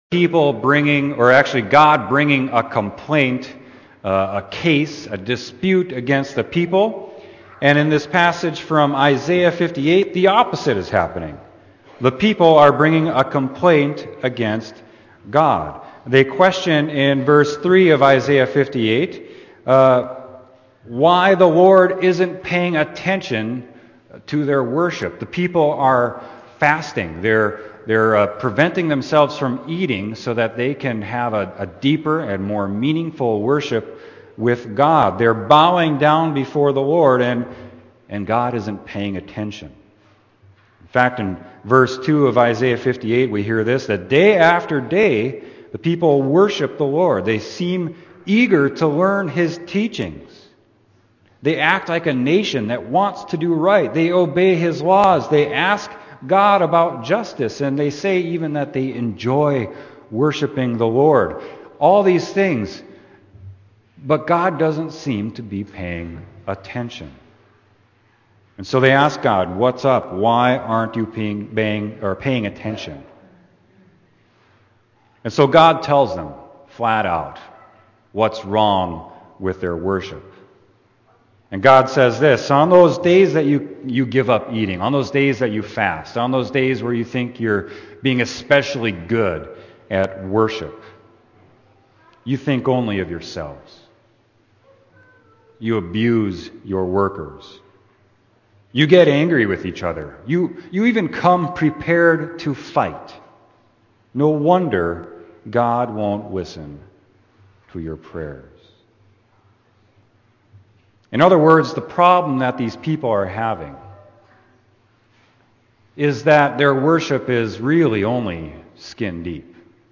Sermon: Isaiah 58.1-9a